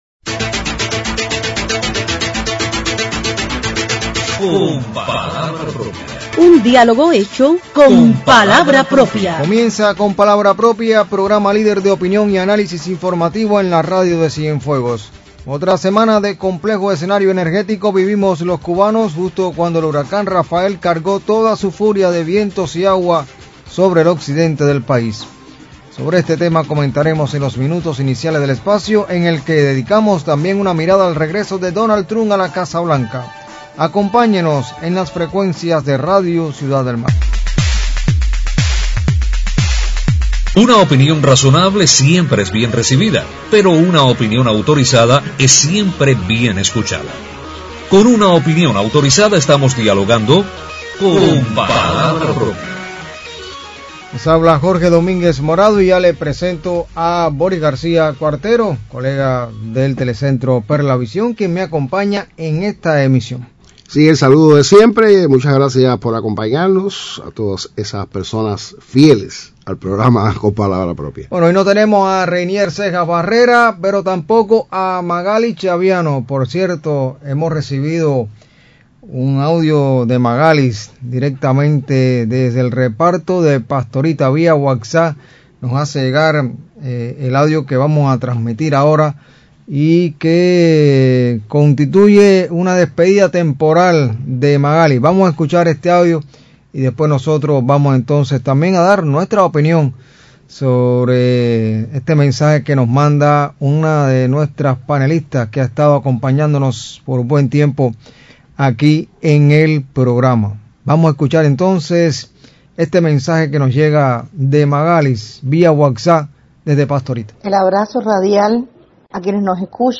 Sobre las acciones de recuperación en Cuba luego del paso devastador del huracán Rafael y la actual contingencia energética que vive la nación comentan los panelistas de Con palabra propia en la emisión del sábado nueve de noviembre.